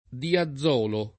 [ dia zz0 lo ]